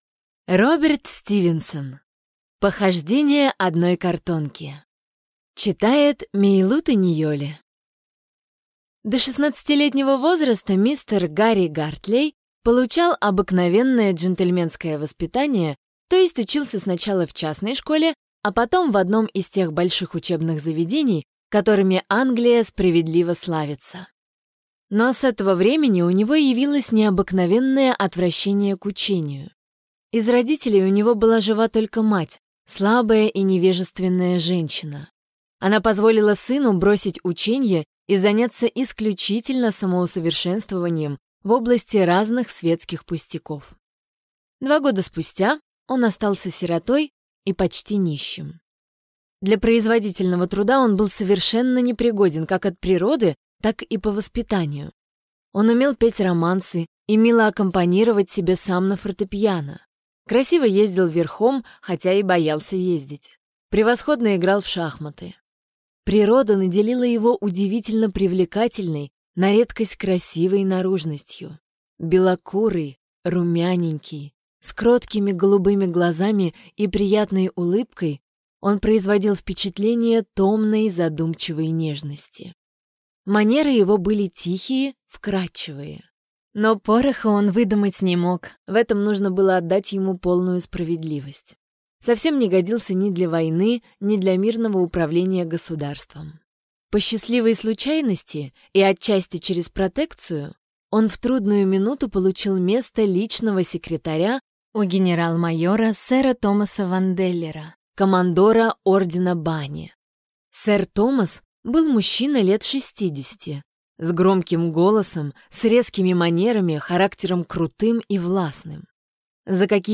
Аудиокнига Похождения одной картонки | Библиотека аудиокниг